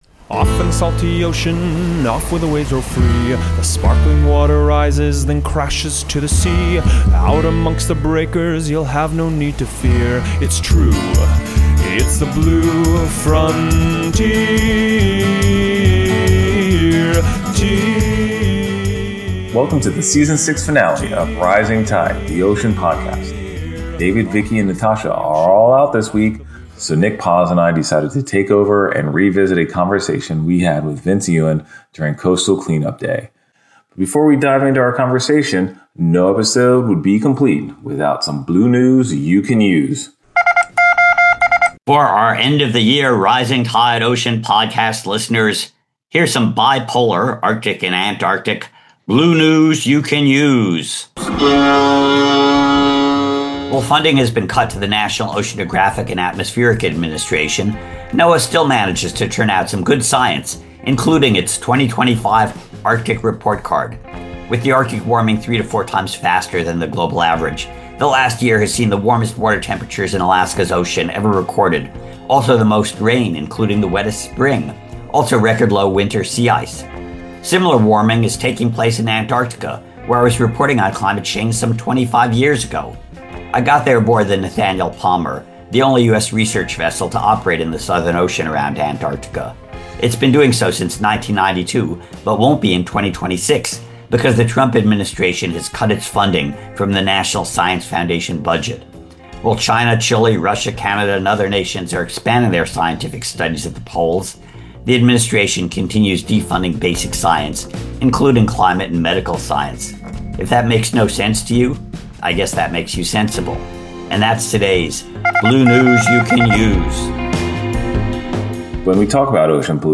on-the-ground (actually on the beach) interview